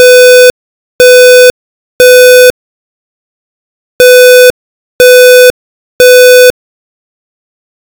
북미 대부분 지역에서 사용되는 표준 화재 경보 소리
이는 반복되는 3-펄스 사이클(0.5초 켜짐, 0.5초 꺼짐, 0.5초 켜짐, 0.5초 꺼짐, 0.5초 켜짐, 1.5초 꺼짐)로 구성된다.